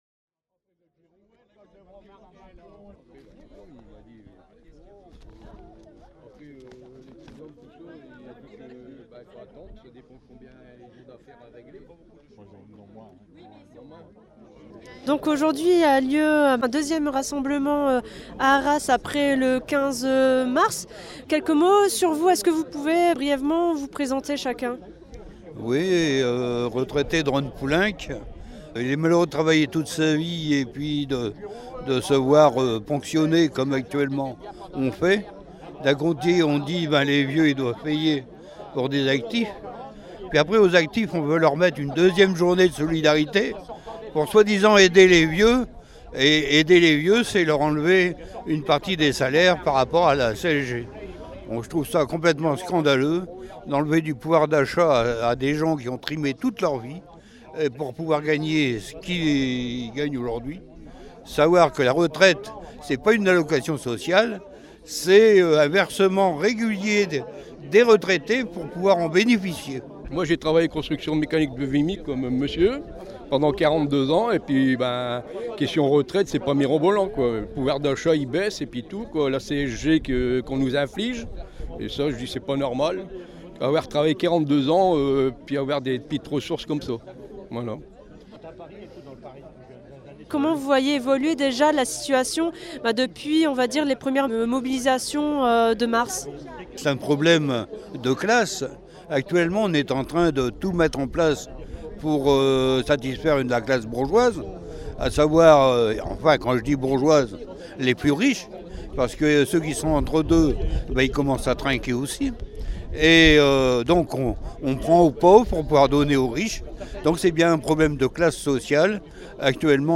Rassemblement des retraités, 14 juin 2018 REPORTAGES/ENTRETIENS
Une manifestation inter-syndicale des retraités a eu lieu le jeudi 14 juin 2018 à 10h, place Courbet à Arras.